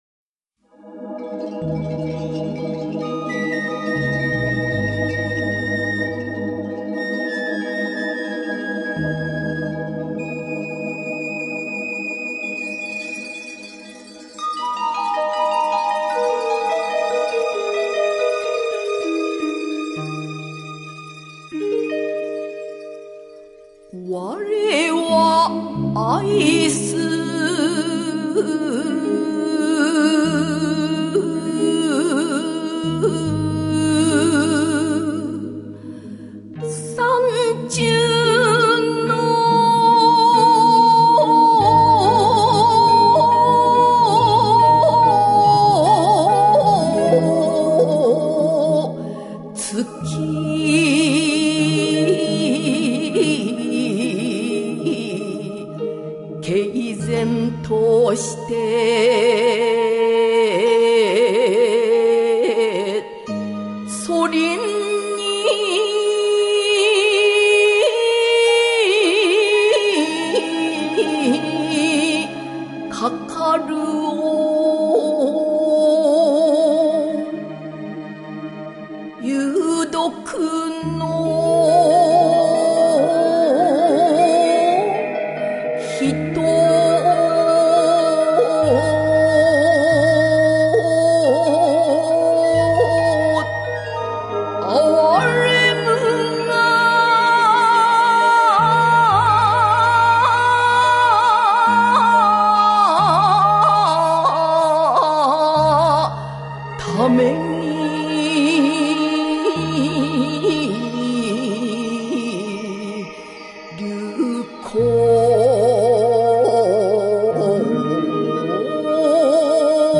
吟者